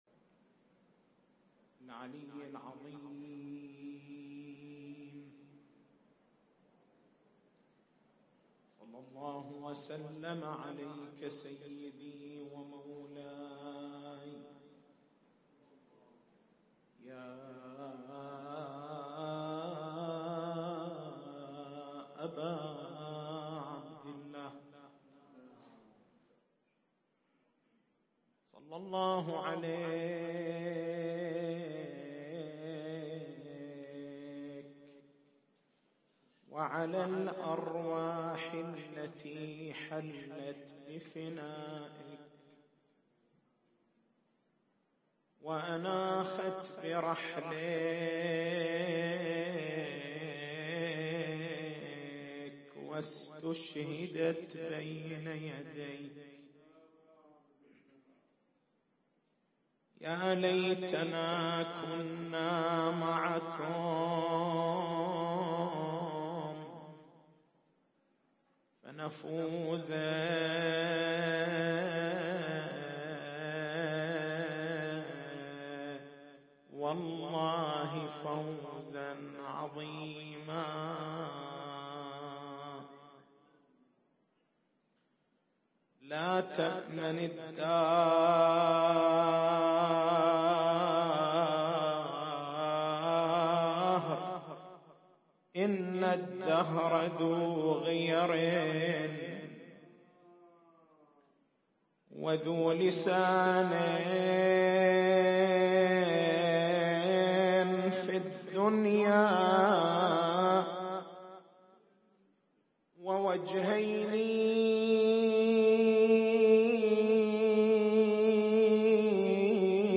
تاريخ المحاضرة: 27/01/1437 نقاط البحث: بيان المقصود من البطون القرآنيّة بيان من له حقّ بيان البطون القرآنيّة واستخراجها التسجيل الصوتي: اليوتيوب: مكتبة المحاضرات > عشرات حسينيّة > العشرة الثالثة 1437